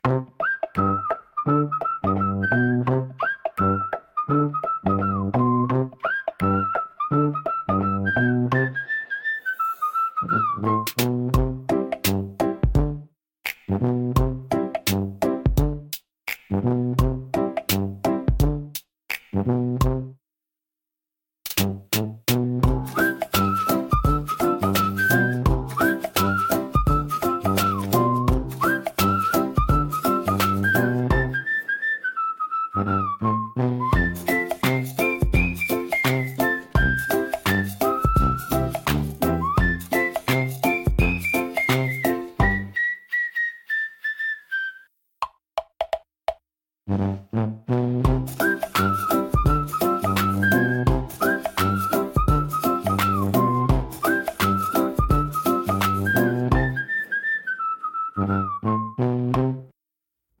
軽快なリズムと遊び心あふれるメロディが、聴く人に楽しさと自由なエネルギーを届けます。
気軽で楽しい空気を作り出し、場を明るく盛り上げるジャンルです。